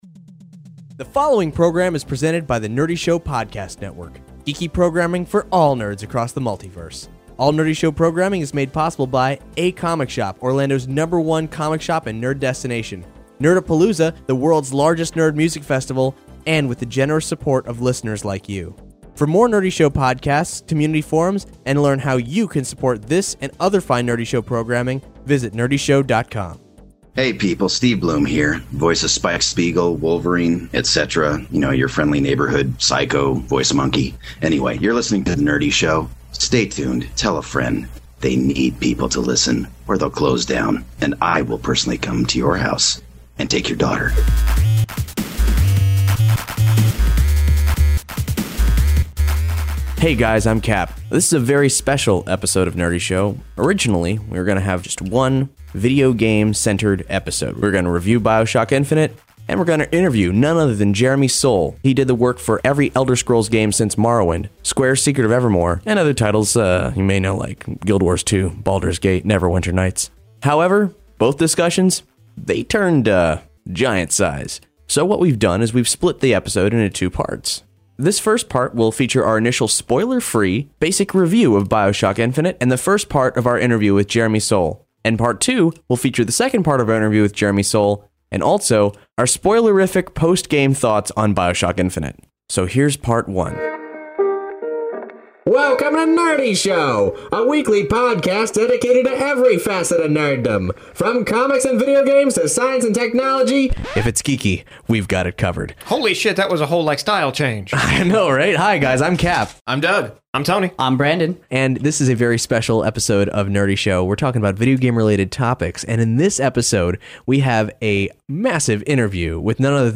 We interview video game composer, Jeremy Soule.